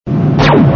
BSG FX - Raider Laser 01
BSG_FX-Cylon_Raider_Laser_01.mp3